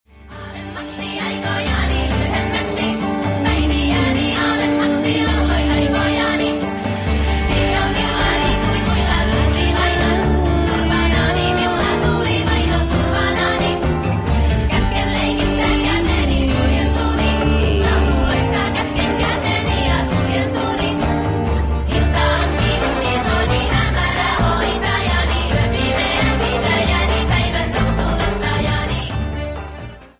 is a sad song